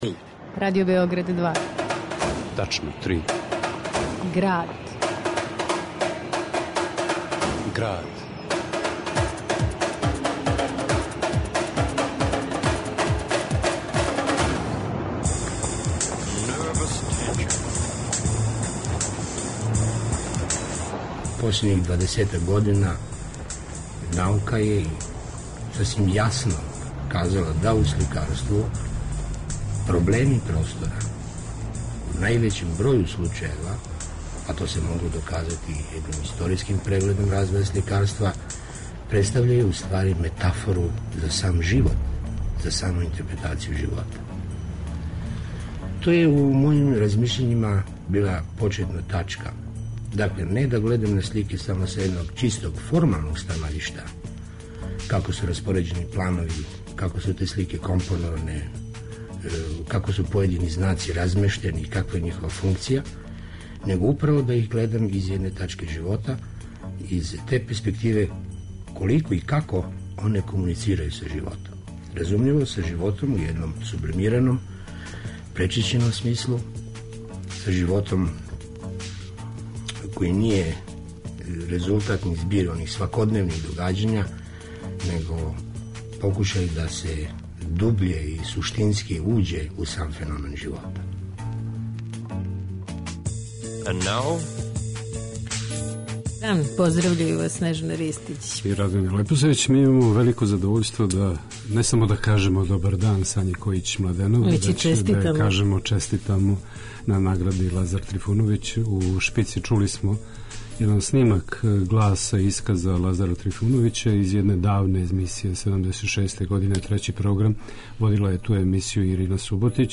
у радијском разговору из 2006...